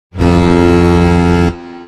Play, download and share Spongebob foghorn original sound button!!!!
spongebob-foghorn_0rxbod9.mp3